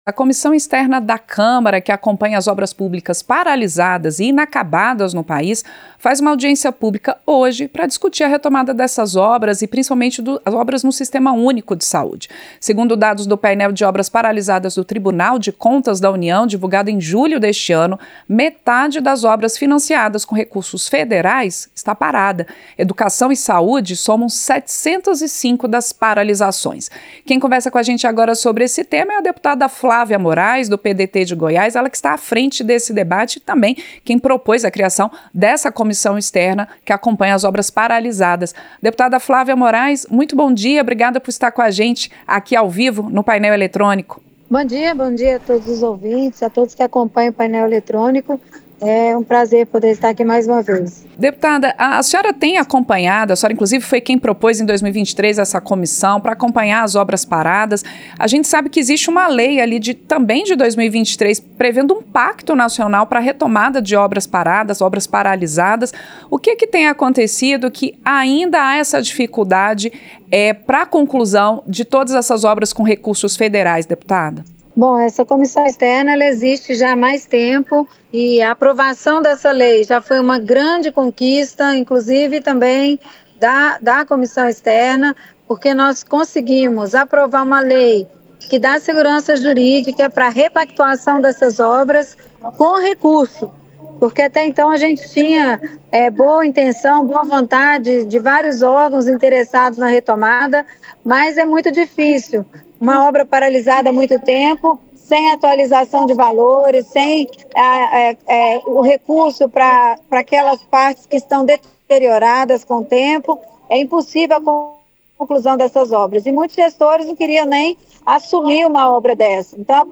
• Entrevista - Dep. Flávia Morais (PDT-GO)
Programa ao vivo com reportagens, entrevistas sobre temas relacionados à Câmara dos Deputados, e o que vai ser destaque durante a semana.